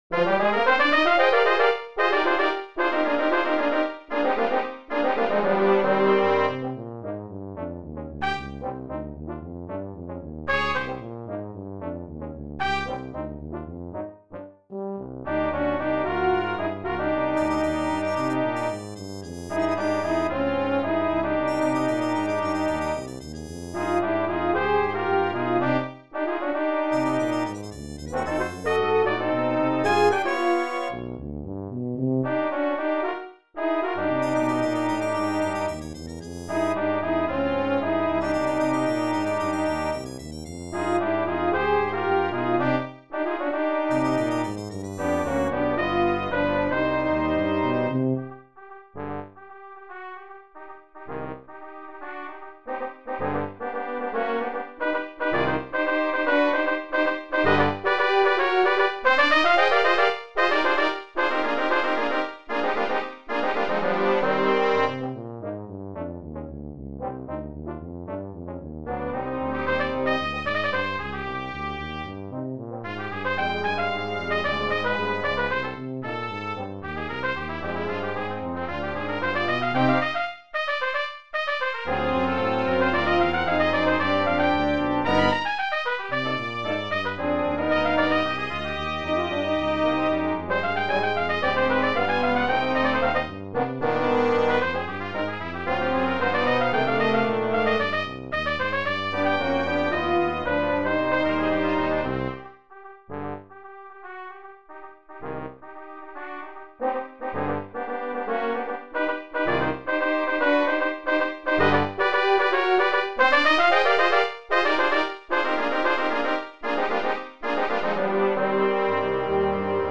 Brass Quintet
Listen to a synthesized sound clip of mvt.1.